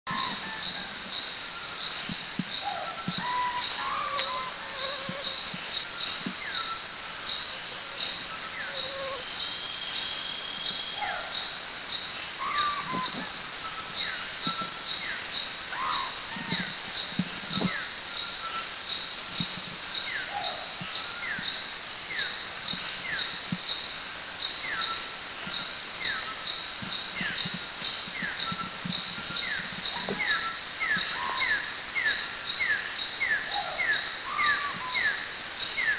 Javan Frogmouth
calls 1
Batrachostomus javensis
Trang Pen. Bot. Garden
JavanFrogmouth.mp3